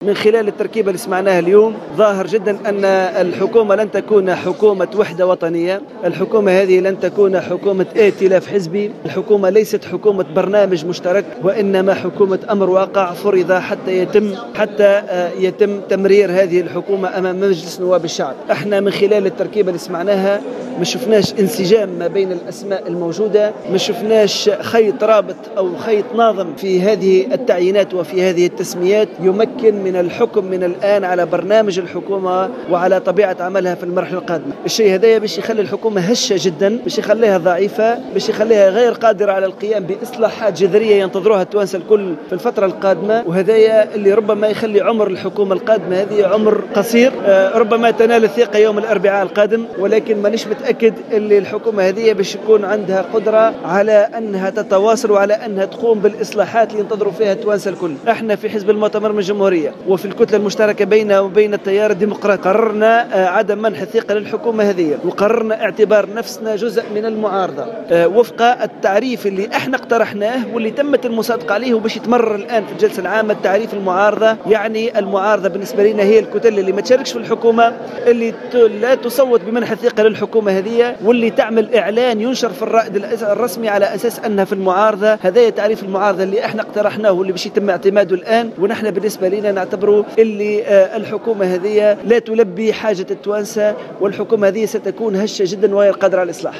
أكد النائب عن حزب المؤتمر من أجل الجمهورية عماد الدايمي في تصريح لجوهرة "اف ام" اليوم الإثنين أن الحكومة التي تم الإعلان عنها اليوم لن تكون حكومة وحدة وطنية ولن تكون حكومة ائتلاف حزبي وليست حكومة برنامج مشترك وإنما حكومة أمر واقع فرض حتى يتم تمريرها امام مجلس نواب الشعب على حد قوله.